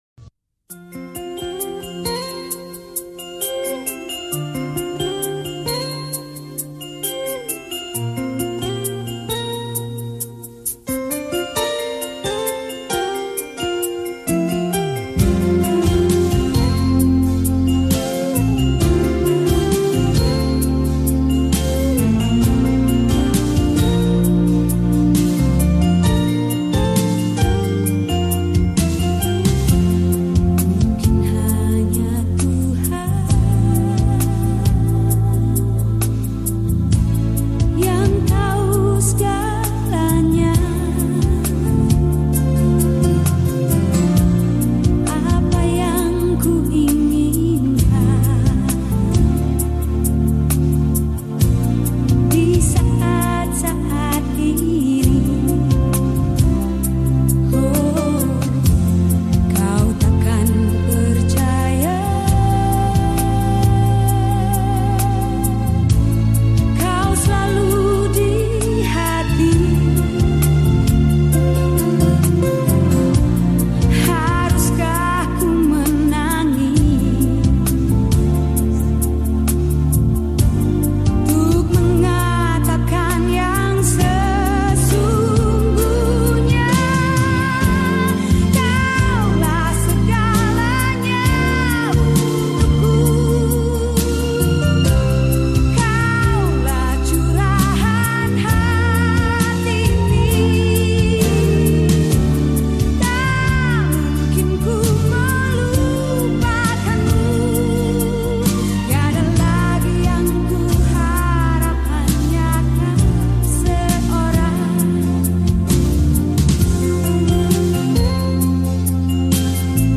Skor Angklung